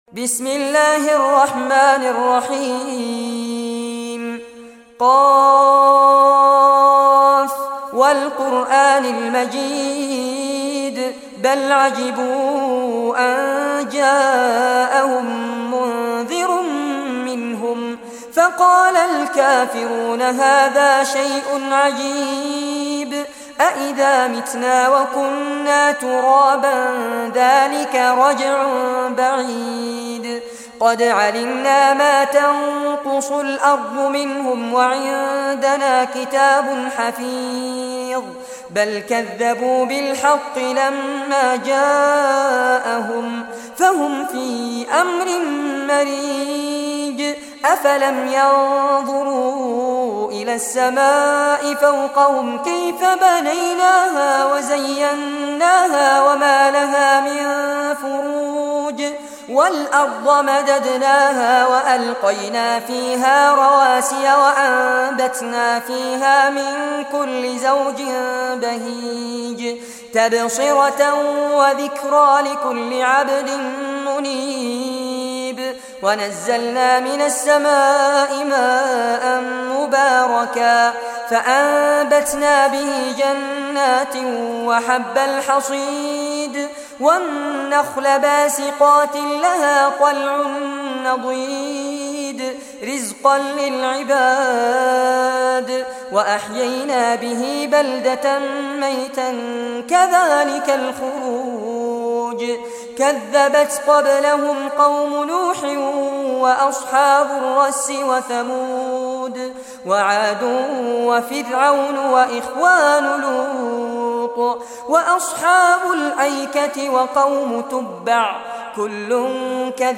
Surah Qaf Recitation by Fares Abbad
Surah Qaf, listen or play online mp3 tilawat / recitation in Arabic in the beautiful voice of Sheikh Fares Abbad.
50-surah-qaf.mp3